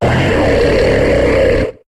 Cri de Kaimorse dans Pokémon HOME.